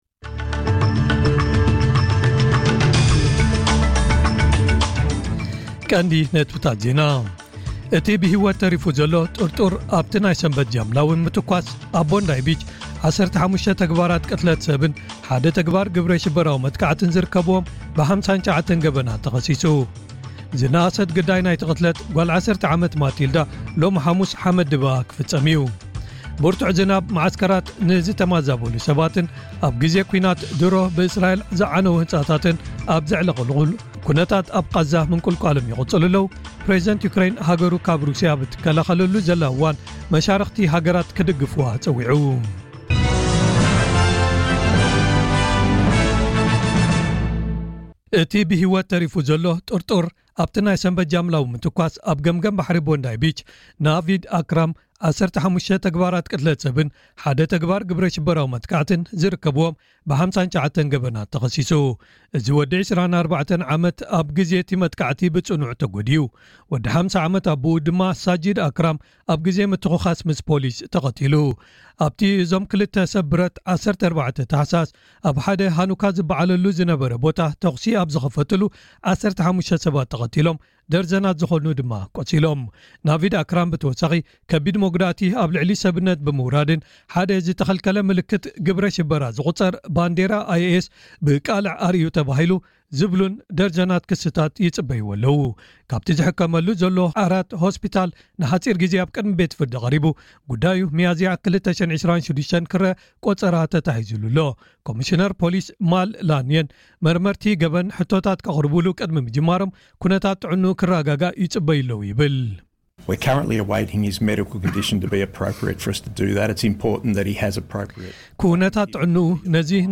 ዕለታዊ ዜና ኤስ ቢ ኤስ ትግርኛ (18 ታሕሳስ 2025)